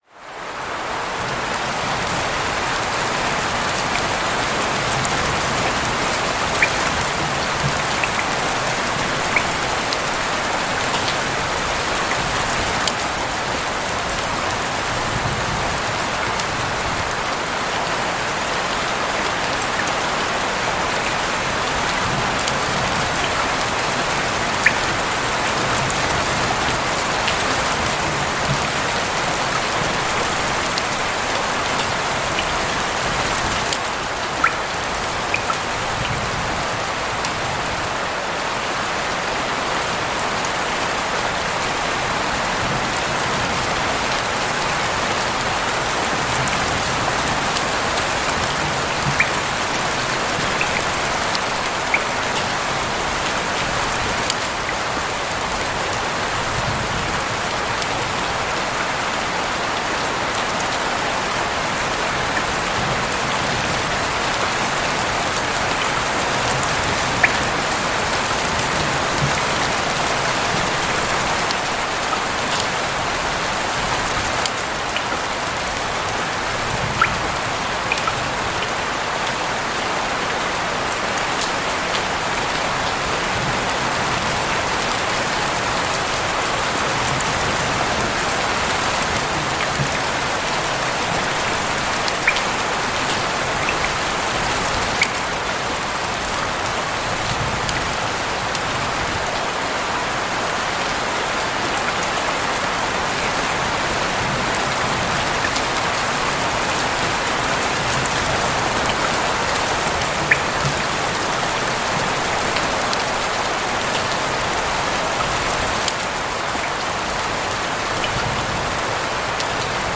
Lluvia y Ruido Verde por una Ventana Abierta para un Descanso Profundo
En Tormenta Relajante, todos los anuncios viven al inicio de cada episodio porque entendemos que la calma no debe romperse cuando apenas empieza a levantarse dentro de ti.